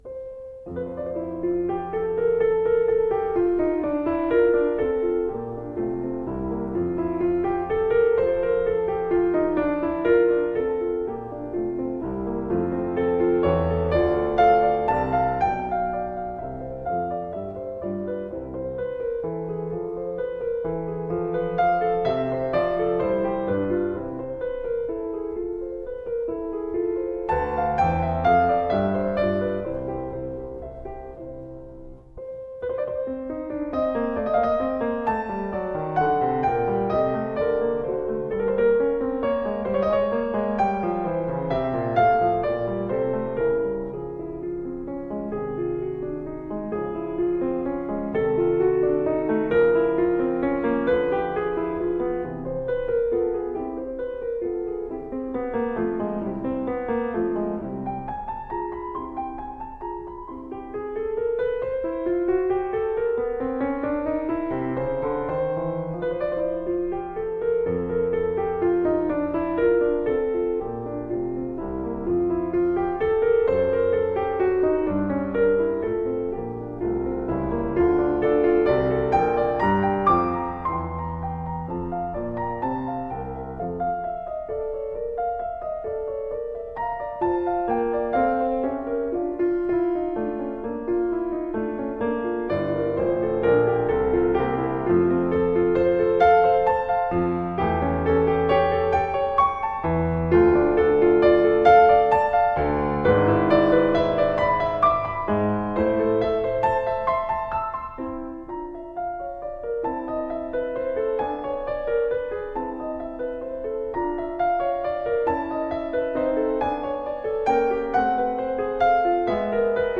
DESCRIPTION OF THE PIECE: a lovely single-movement piano piece in rondo form.
The B section features mostly sixteenth notes (by comparison, the A section is mostly eighth notes in 6/8 time) and can be subdivided essentially the same as the A section, but because it is new musical material, we have to use different letters—in this case, c-d-cˈ and the cˈ is quite extended.
Rondo B